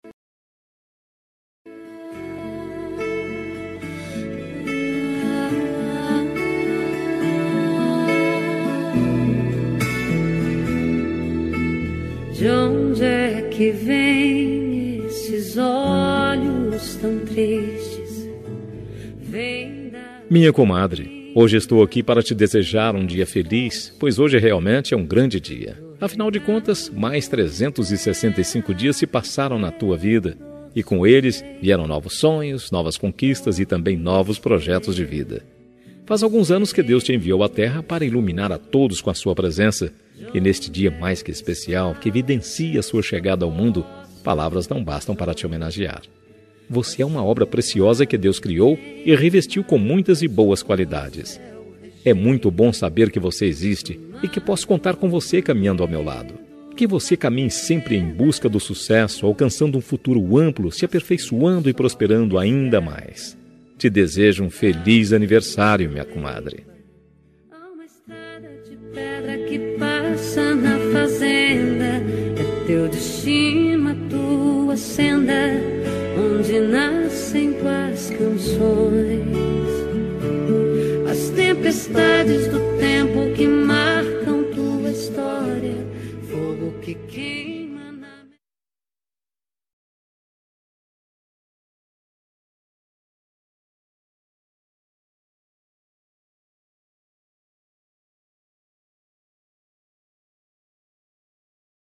Aniversário de Comadre – Voz Masculina – Cód: 202145